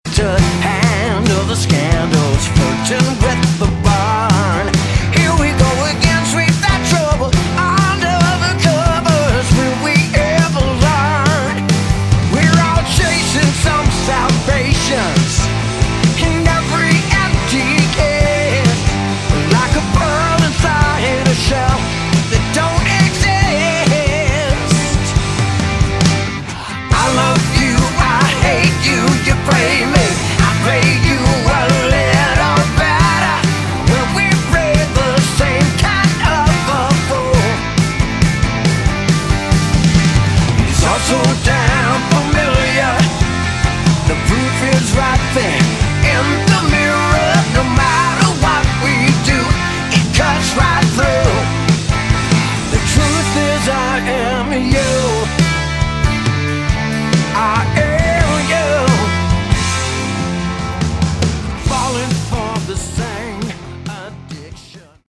Category: Melodic Rock
bass
vocals
drums
guitars